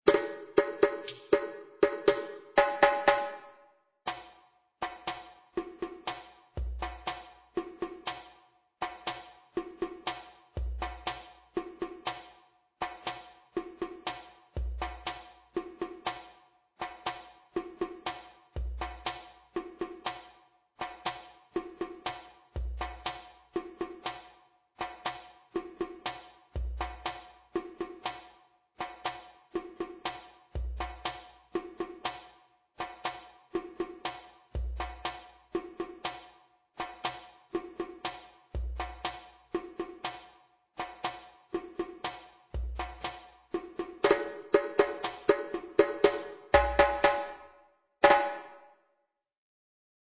Djagbe djembe 2
Djagbe-djembe-2.mp3